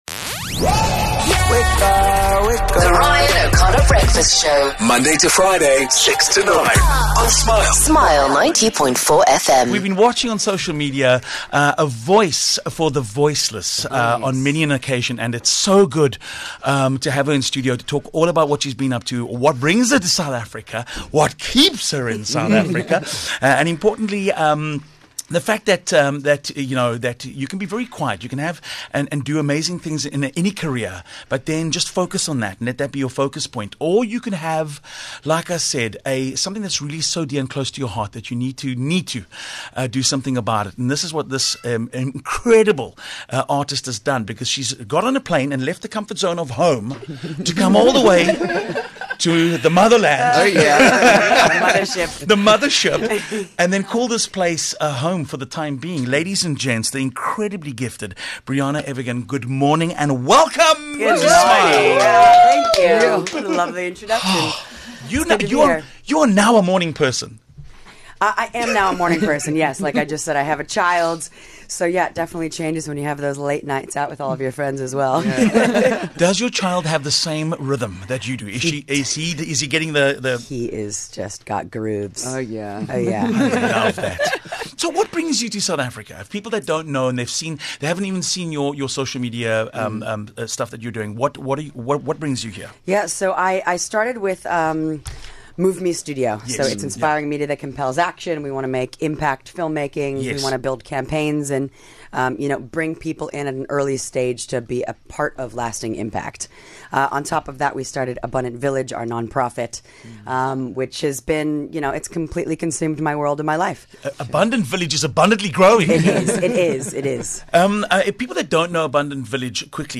Passionate about using her voice for good, actor Briana Evigan has been on a quest to give a voice to the voiceless, shine a light on darkness and create a positive impact. She's taken some time from Hollywood to visit South Africa and popped into our studios for a visit.